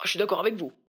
VO_ALL_Interjection_19.ogg